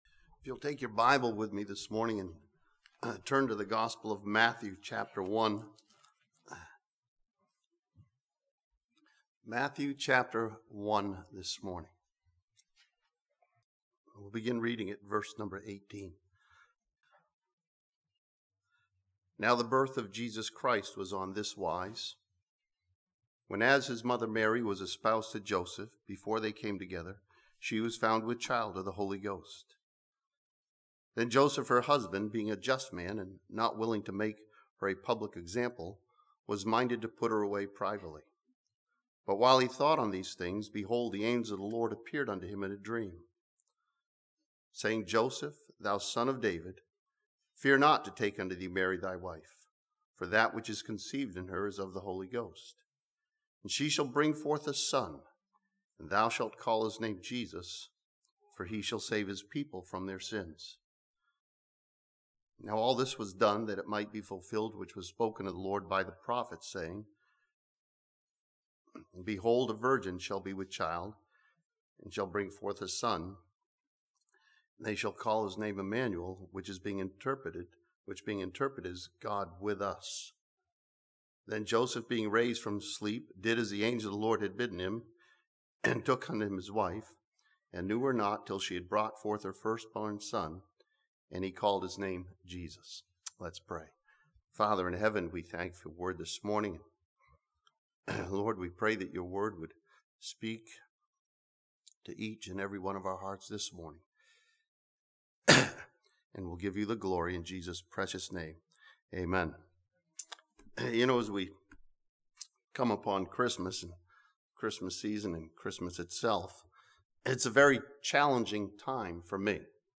This sermon from Matthew chapter 1 starts with the miracle of Christmas and finds the message and meaning in it.